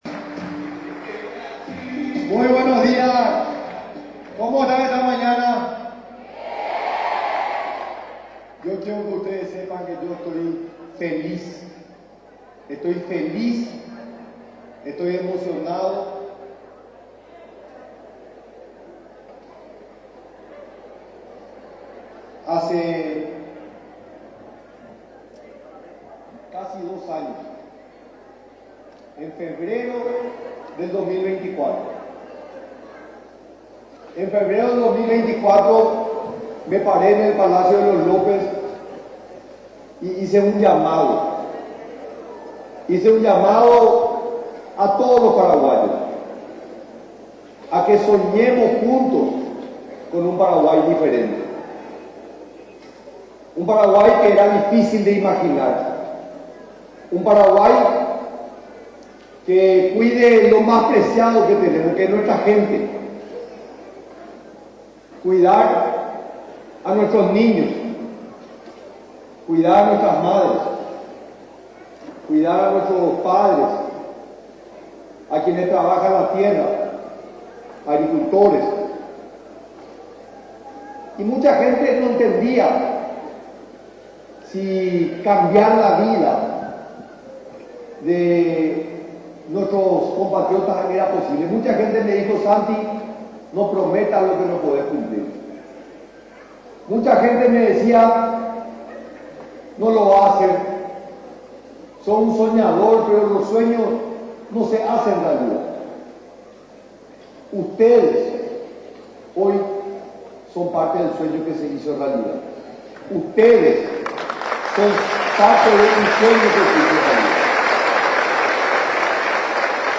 Durante el acto de clausura realizado en el departamento de Paraguarí, resaltó que el Gobierno en este 2025, entregó unos 200 millones de raciones a más de un millón de estudiantes de todo el país.